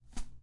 描述：把书放在枕头上
Tag: 枕头